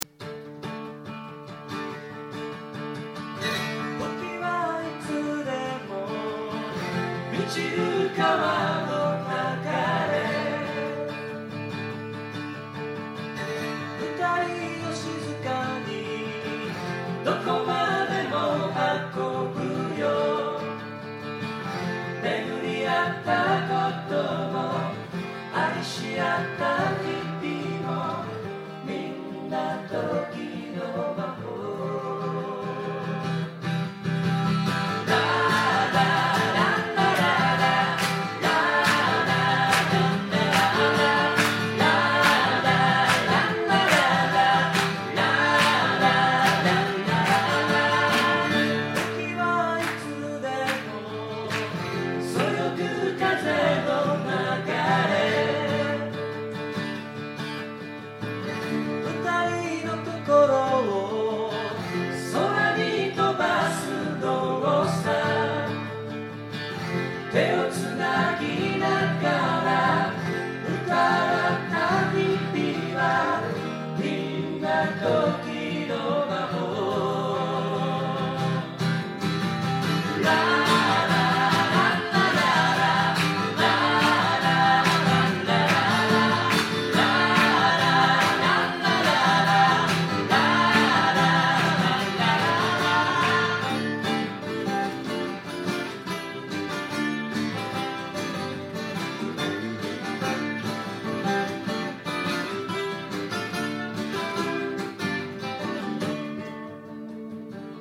愛知県名古屋市　「アートピア」
今回の録音は多少レベルが低かったので、パソコンのボリュームを